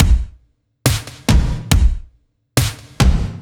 Index of /musicradar/french-house-chillout-samples/140bpm/Beats
FHC_BeatB_140-01_KikSnrTom.wav